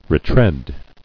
[re·tread]